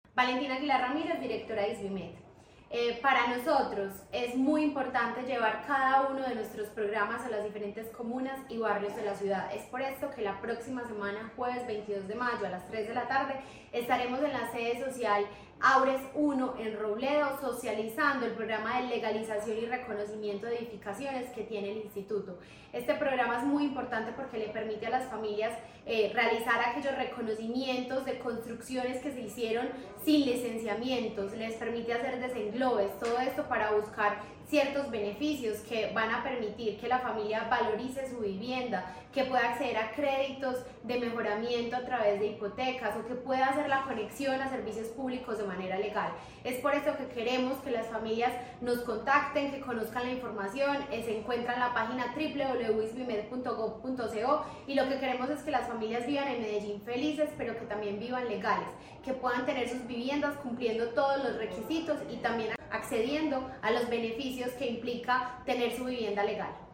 Audio-Declaraciones-de-la-directora-del-Isvimed-Valentina-Aguilar-Ramirez.mp3.mp3